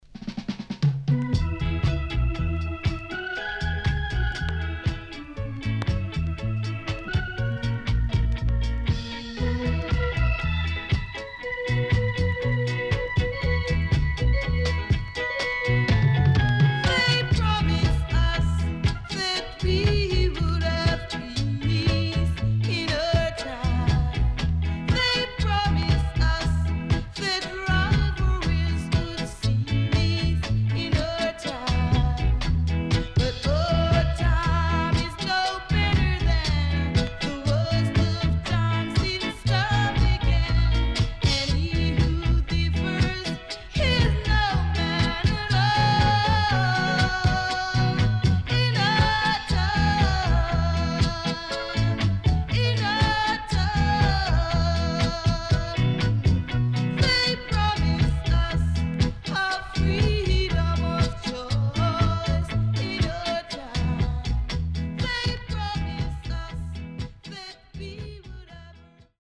HOME > REGGAE / ROOTS  >  SWEET REGGAE
SIDE A:少しチリノイズ入ります。